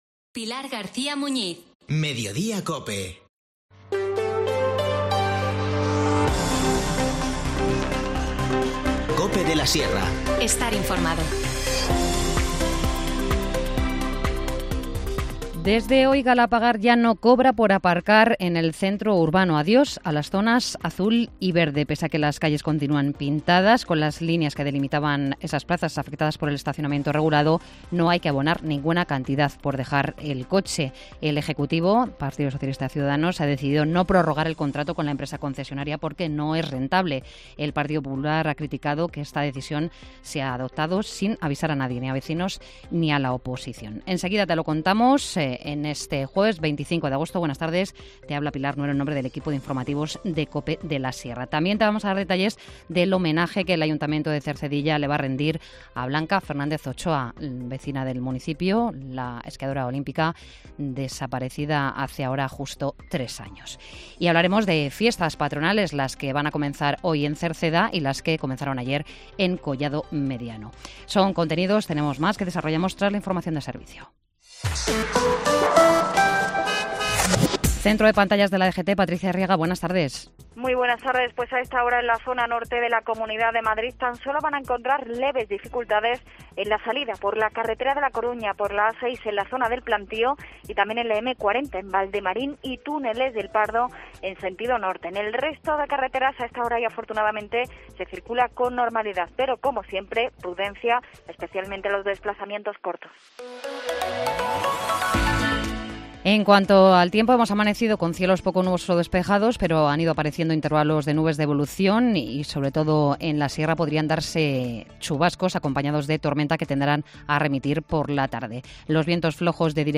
Informativo Mediodía 25 agosto